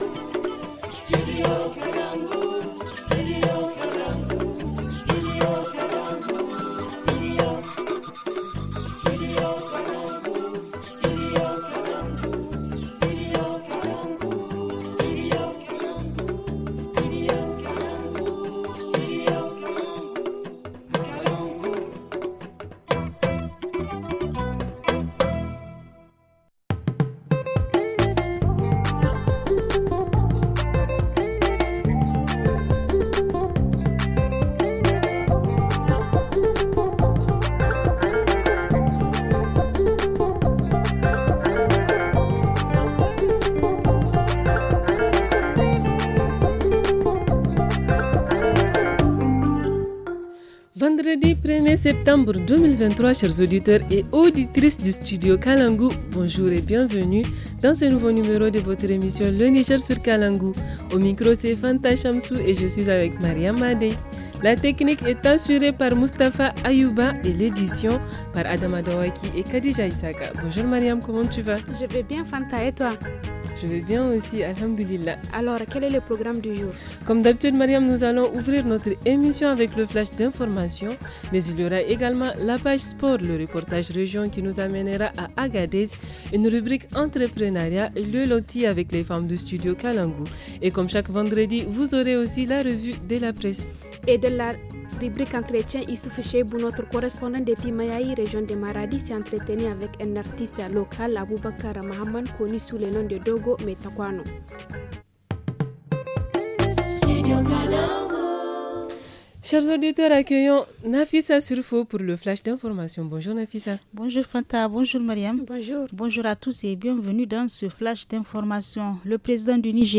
-Reportage Région : Consommation des stupéfiants par les jeunes filles dans la région d’Agadez ;